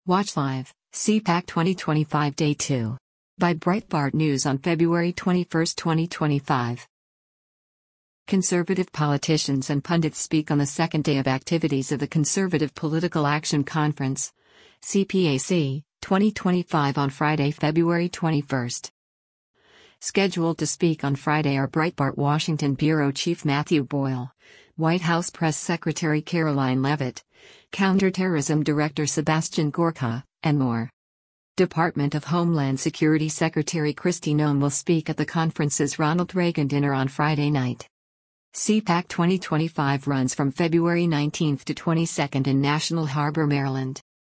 Conservative politicians and pundits speak on the second day of activities of the Conservative Political Action Conference (CPAC) 2025 on Friday, February 21.